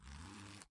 拉链 " zipper4
描述：金属拉链从钱包或裤子被拉开
Tag: 金属 钱包 裤子 拉链 拉链